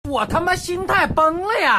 我***心态崩了呀音效免费音频素材下载